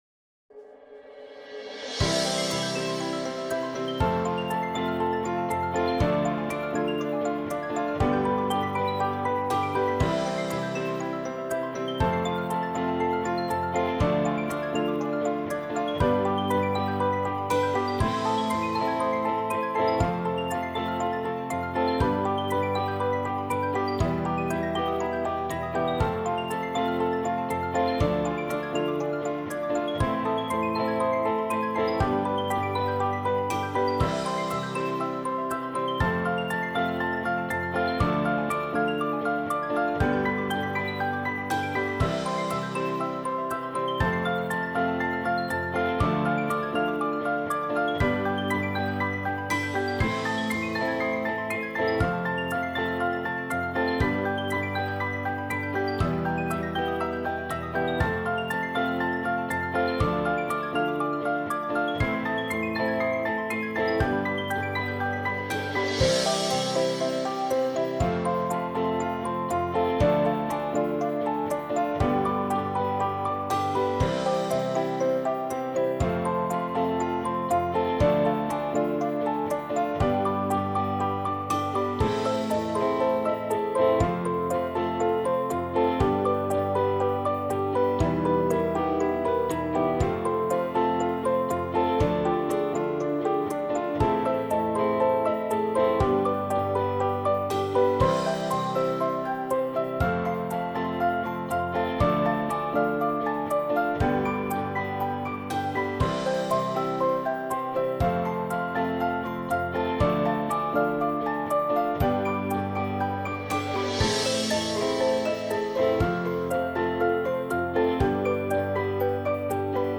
🎵 Trilha Corporativa